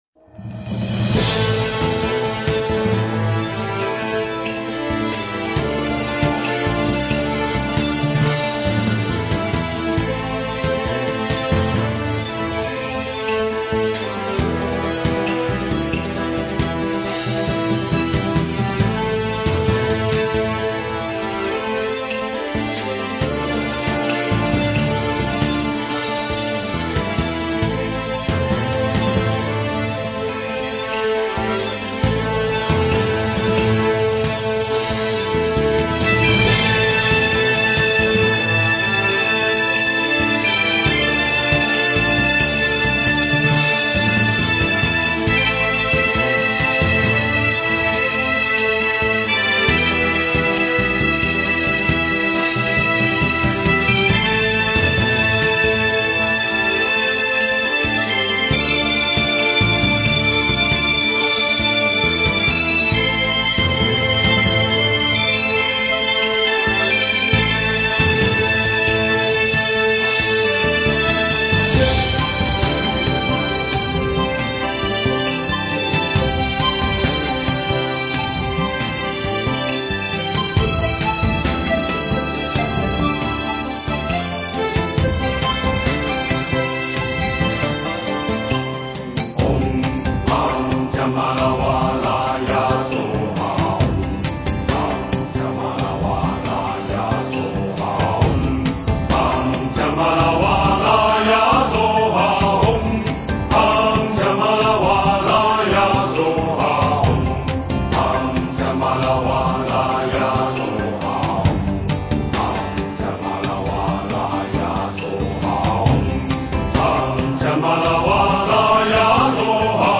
时轮金刚咒 诵经 时轮金刚咒--佛教音乐 点我： 标签: 佛音 诵经 佛教音乐 返回列表 上一篇： 三宝歌 下一篇： 無相頌2 相关文章 地藏经-较量布施功德缘品第十 地藏经-较量布施功德缘品第十--佛经...